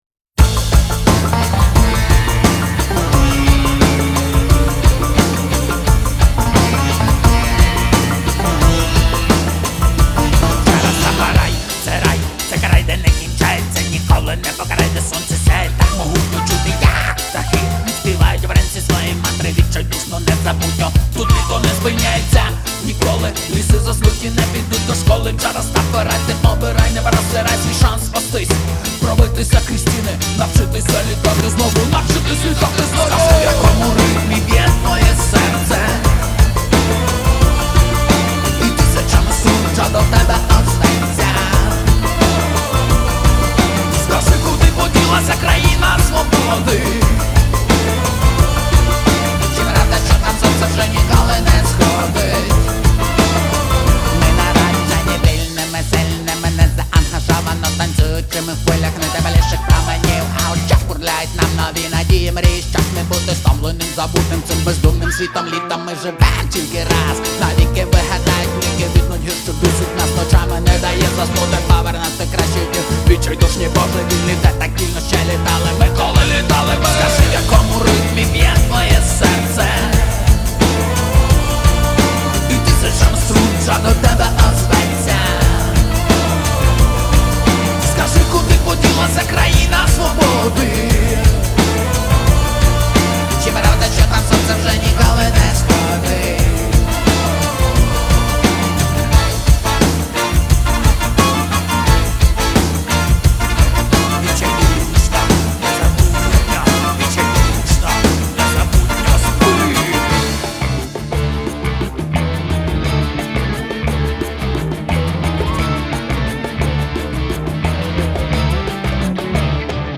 • Жанр: Indie